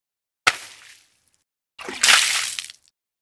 menuclick.mp3